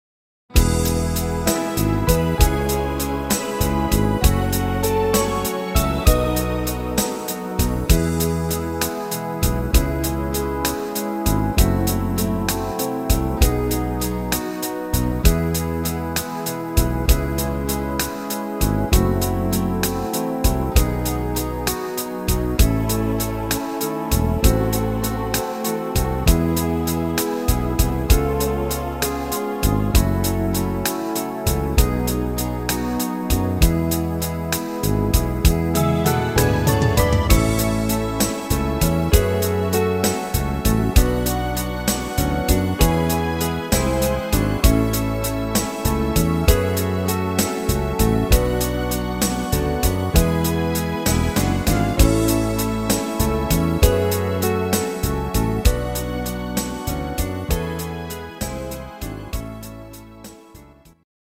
Instrumental Gitarre